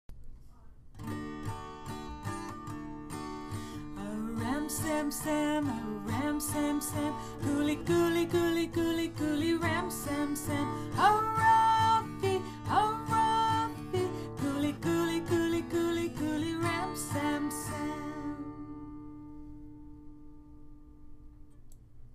In large group, sing “A Ram Sam Sam,” a popular children’s song which originated in Morocco: